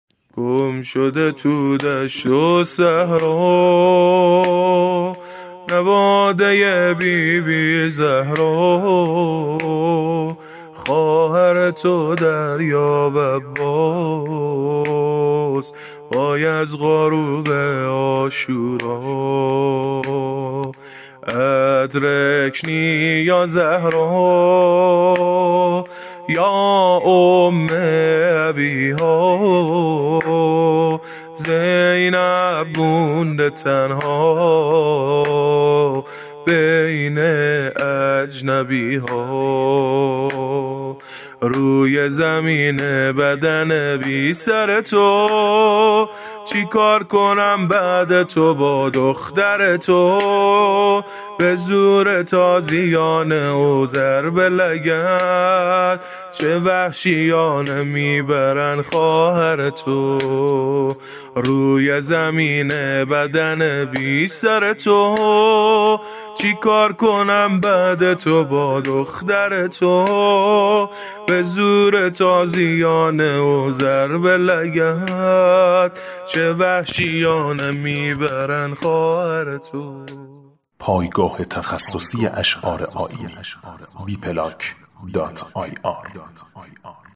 زمینه بهمن عظیمی